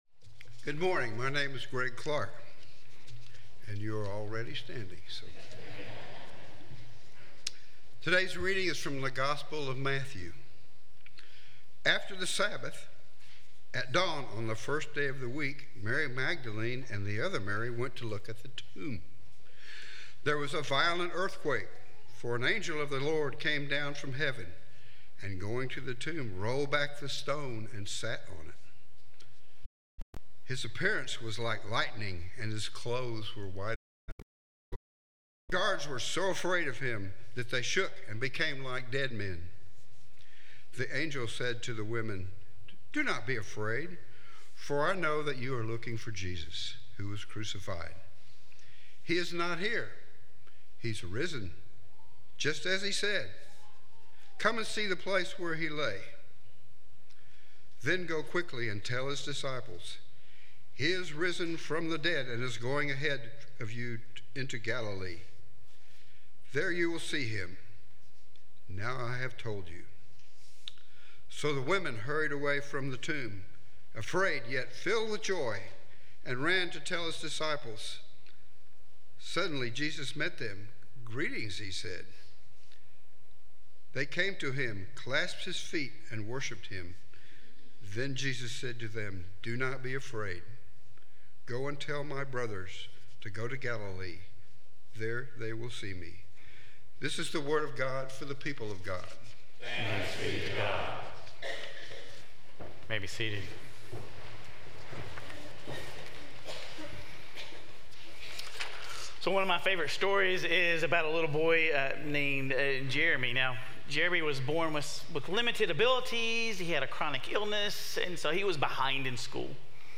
Sermon Reflections: What is your source of hope?